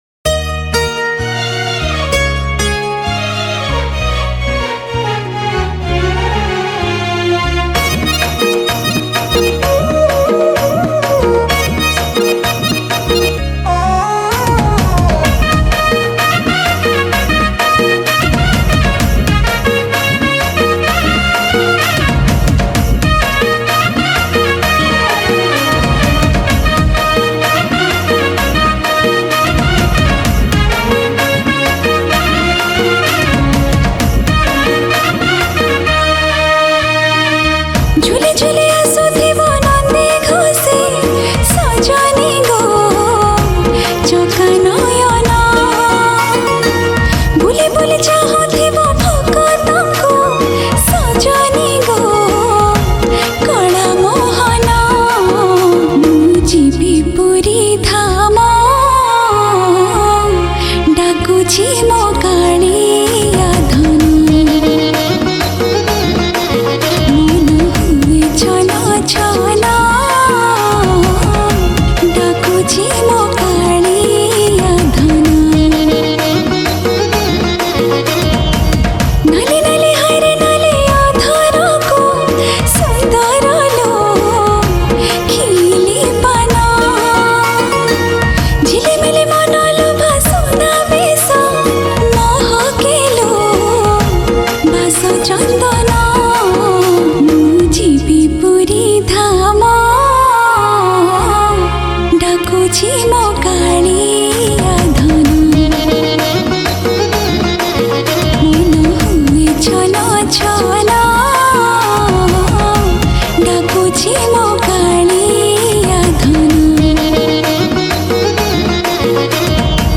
Odia New Bhajan 2025